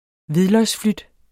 Udtale [ ˈviðlʌjs- ]